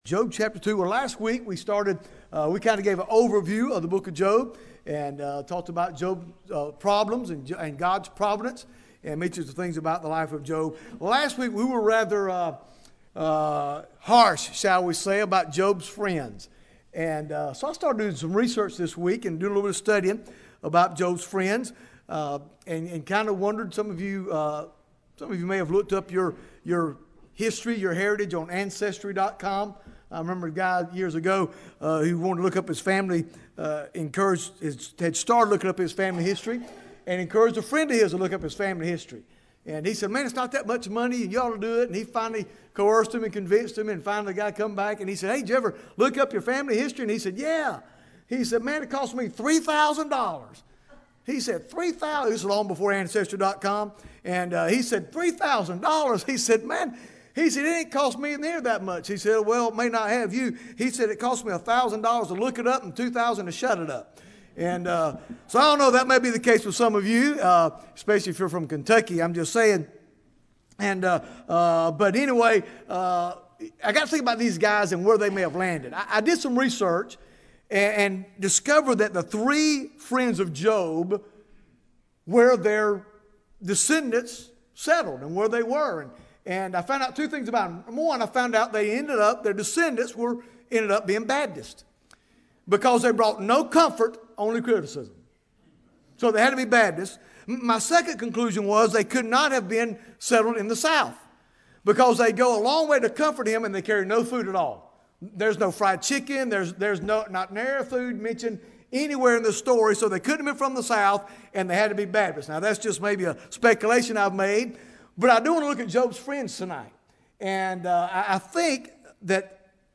Bible Text: Job 2 | Preacher